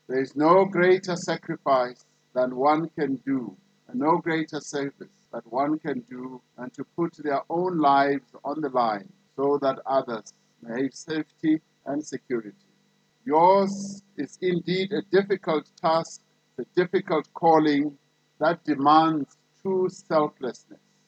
During the annual South African Police Service Commemoration Day, Ramaphosa said more would also be done to bring to book those who are responsible for killing police officers.